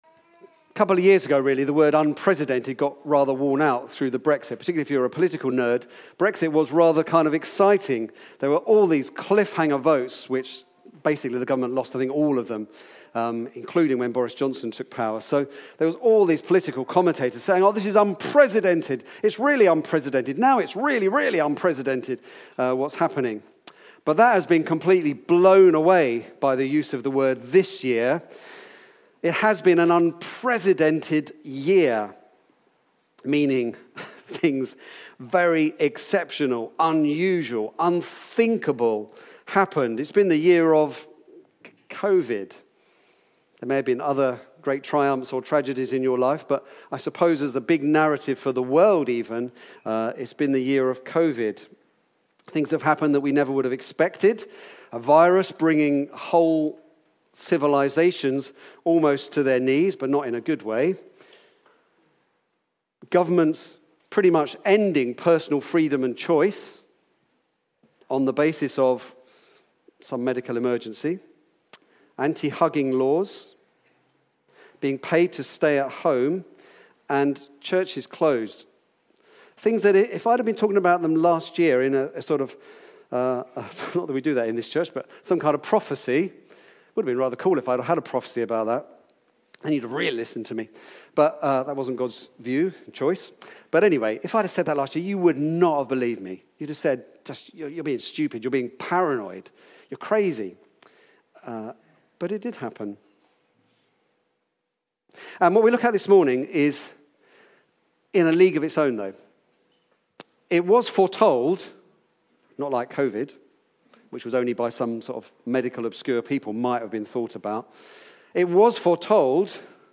An independent evangelical church